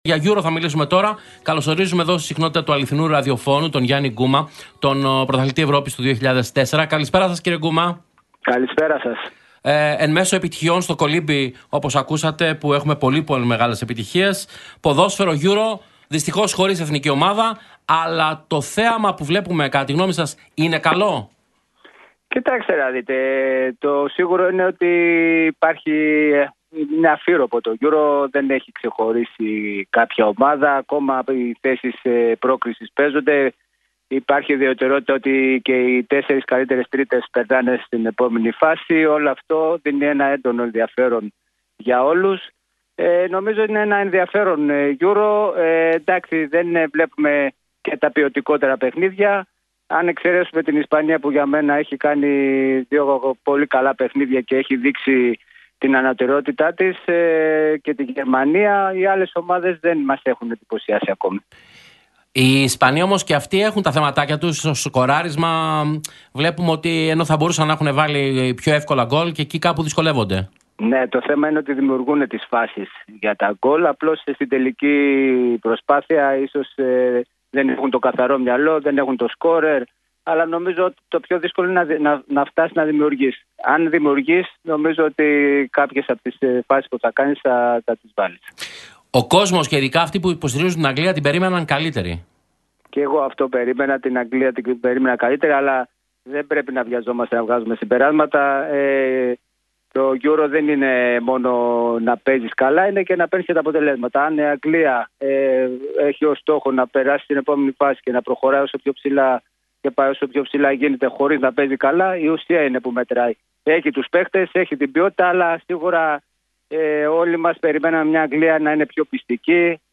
Την Ισπανία χαρακτήρισε ως φαβορί για την κατάκτηση του Euro 2024 ο Γιάννης Γκούμας, μιλώντας στον Realfm 97,8, τονίζοντας ότι παρακολουθούμε μια αμφίρροπη διοργάνωση.